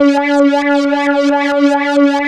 3100 AP  C#5.wav